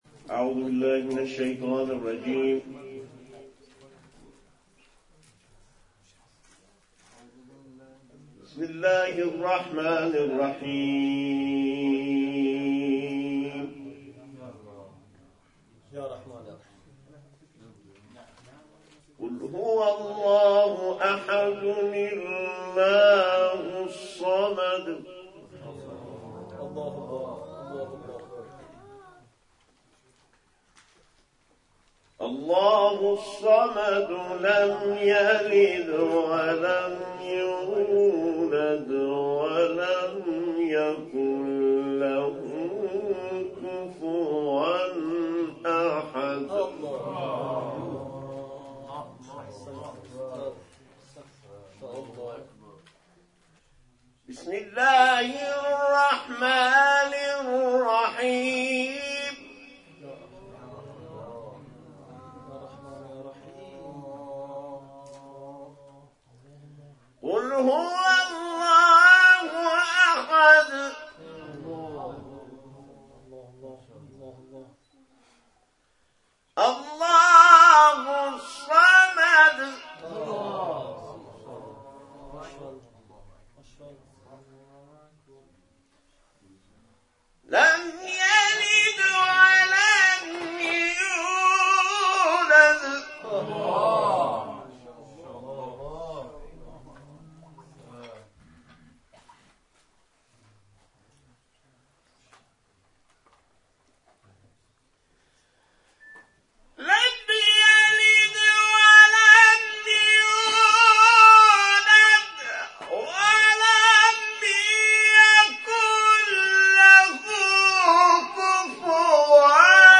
در ادامه تلاوت‌های این جلسه ارائه می‌شود.